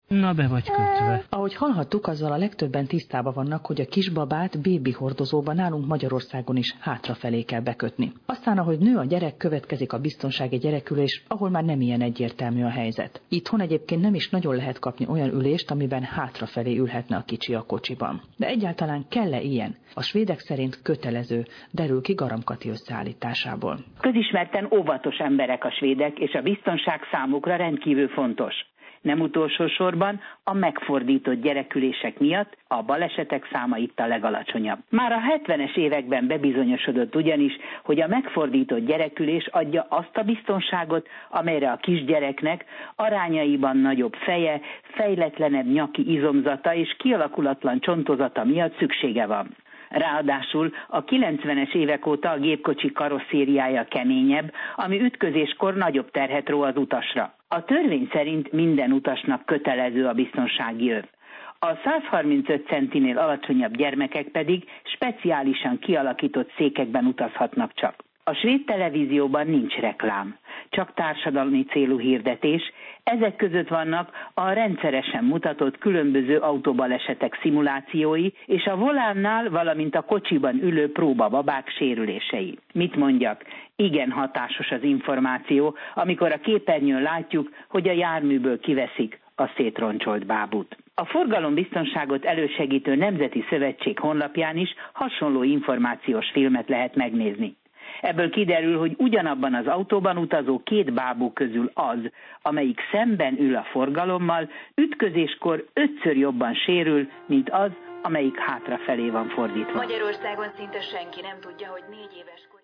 A rádióriportban